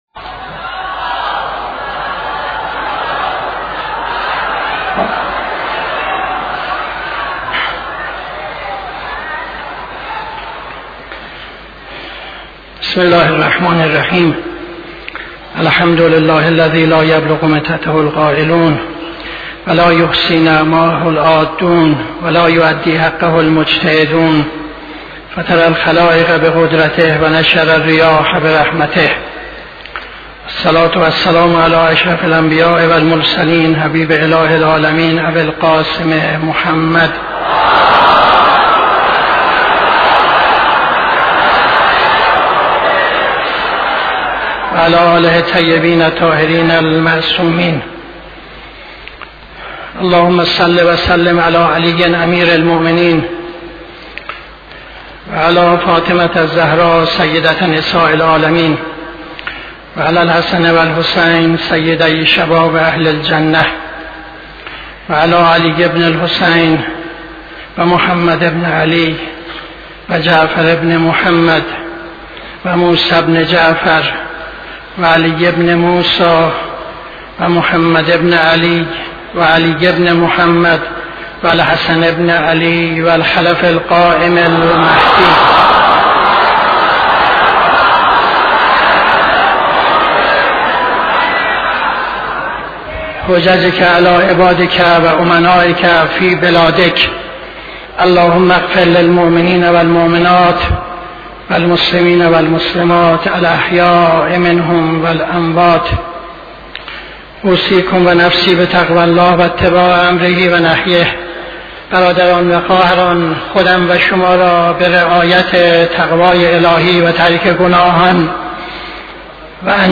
خطبه دوم نماز جمعه 10-01-75